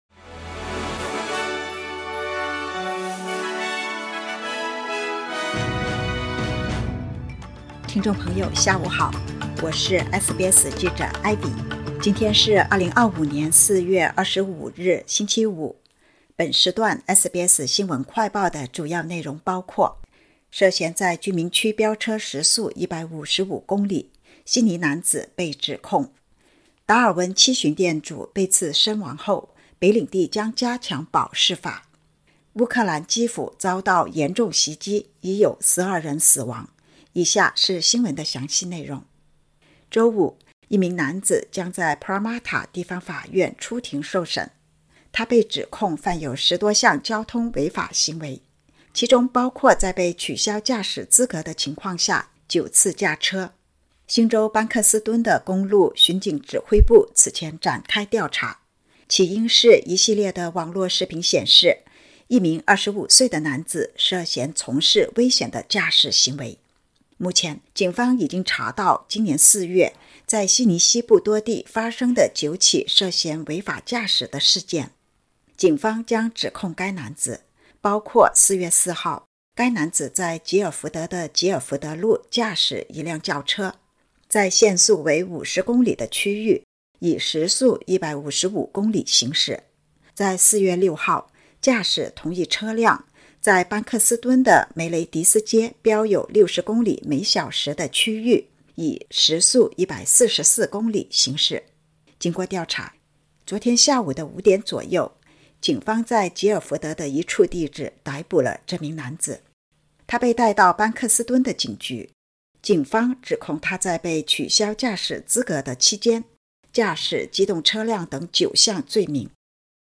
【SBS新闻快报】涉嫌在50公里限速区飙车时速155公里 悉尼男子被指控